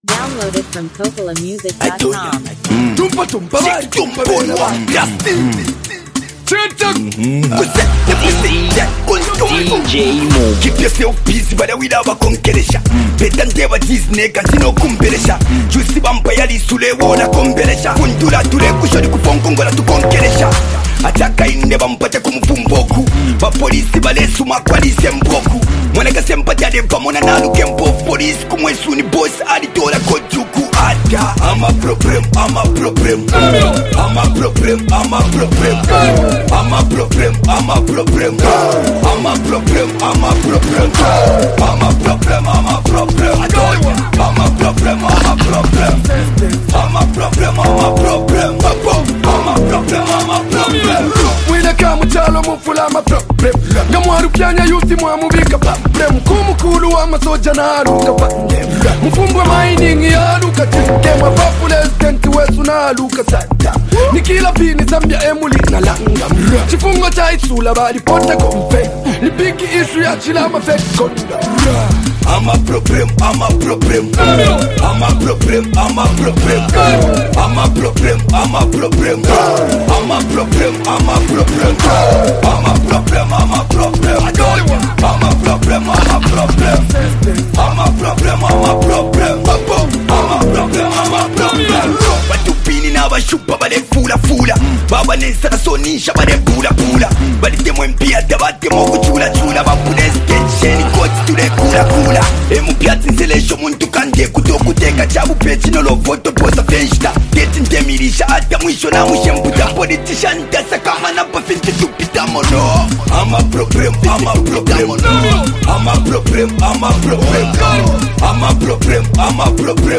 a bold and energetic track